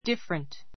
different 中 A1 dífərənt ディ ふァレン ト 形容詞 比較級 more different 最上級 most different 違 ちが った , 別の; いろいろな , 別々の 関連語 「違う」は differ , 「違い」は difference .